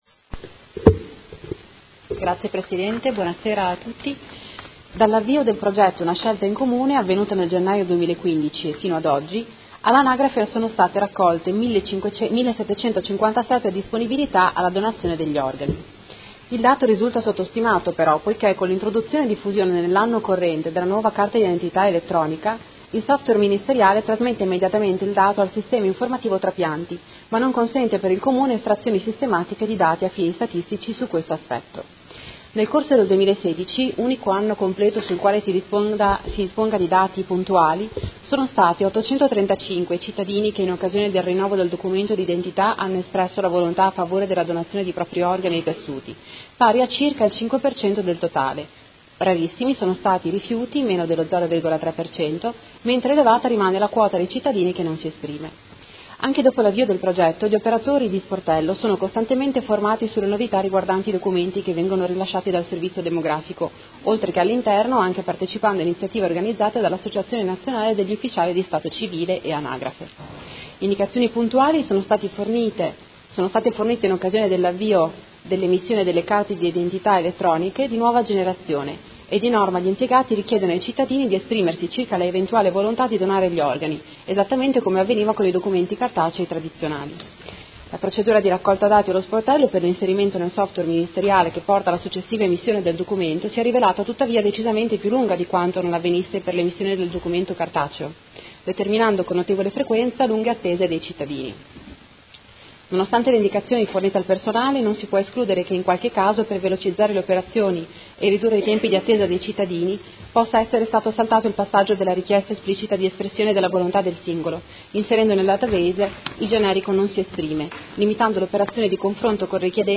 Seduta del 23/11/2017 Risponde. Interrogazione della Consigliera Arletti (PD) avente per oggetto: Come sta procedendo l’attuazione del progetto sulla dichiarazione di volontà alla donazione di organi all’anagrafe denominato “Una scelta in Comune”?